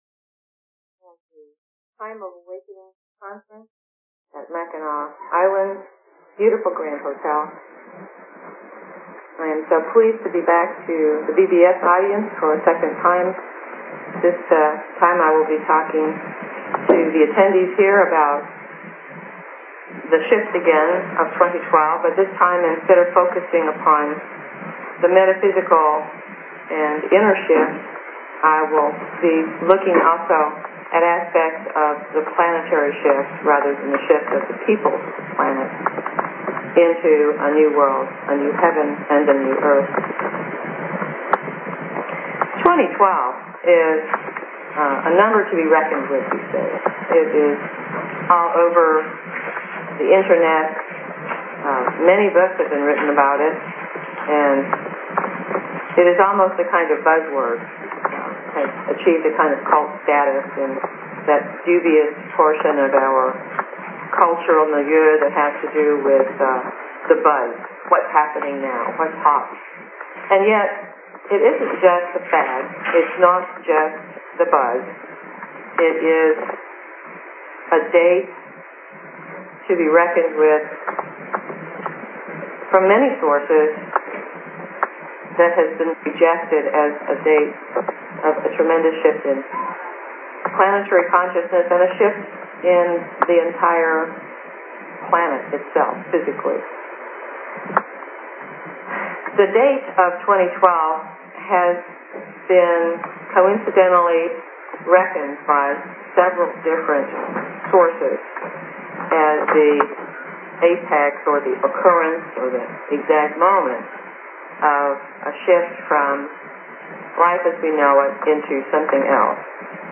Talk Show Episode, Audio Podcast, LLResearch_Quo_Communications and Courtesy of BBS Radio on , show guests , about , categorized as
A Time of Awakening Conference - Mackinac Island, Michigan